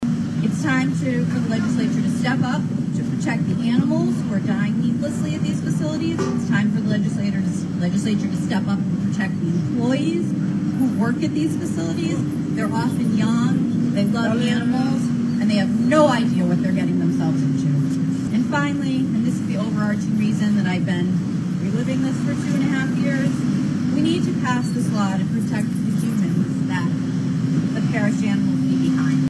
A rally was held in front of the State House on Wednesday in support of Ollie’s Law which calls for statewide standards on the so-called “doggie day care industry” in Massachusetts.